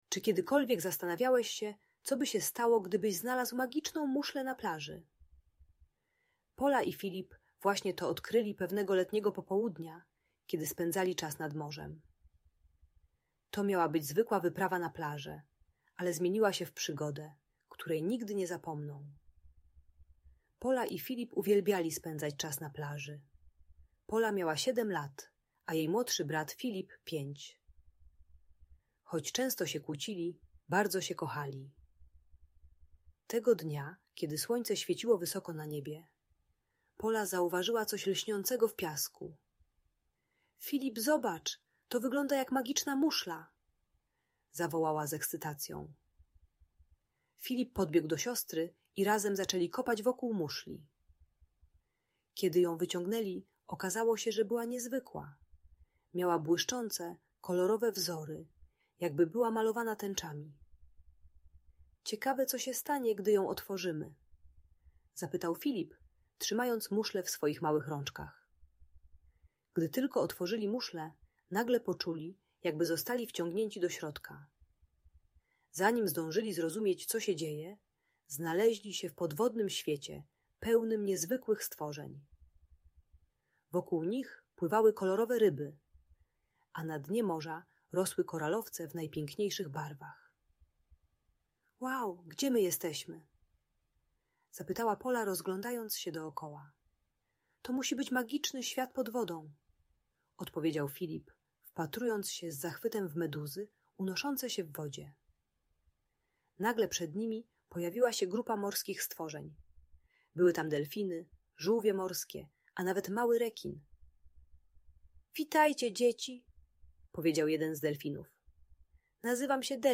Magiczna muszla - Agresja do rodziców | Audiobajka